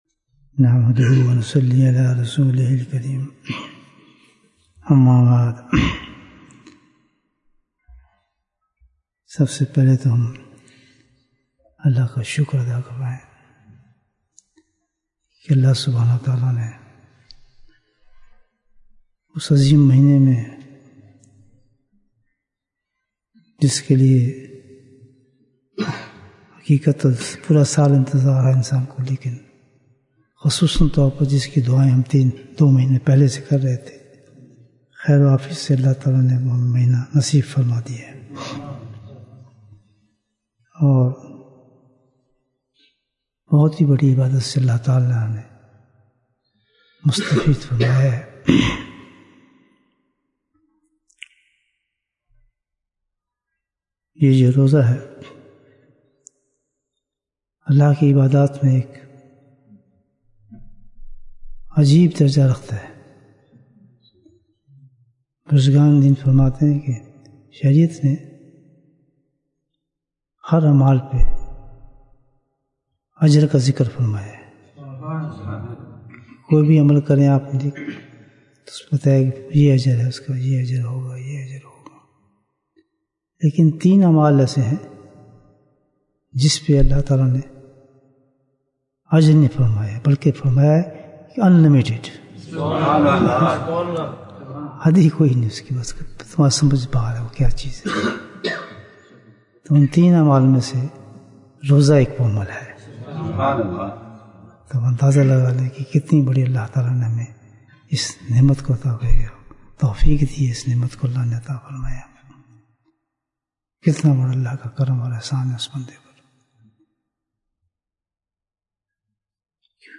Bayan, 17 minutes 2nd April, 2022 Click for English Download Audio Comments What is the Reward for Fasting?